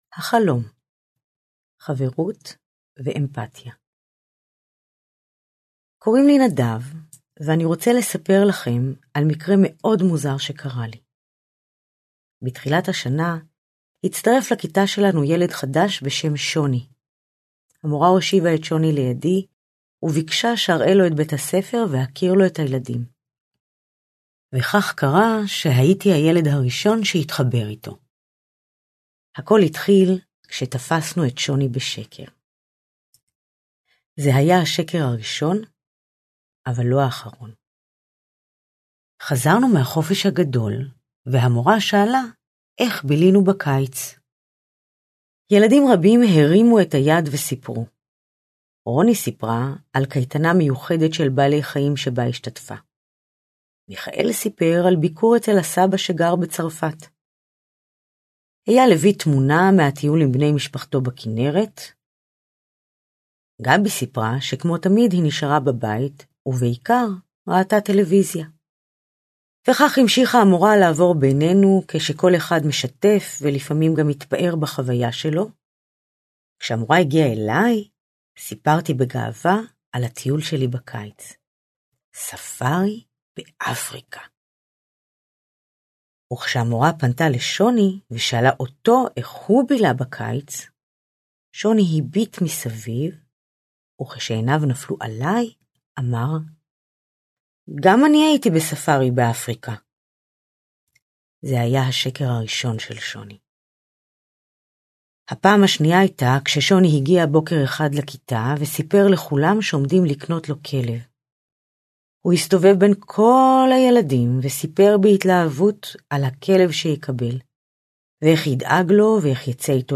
מדריך אודיו לילדים עם סיפורים מרגיעים לפיתוח הדמיון הביטחון העצמי ורוגע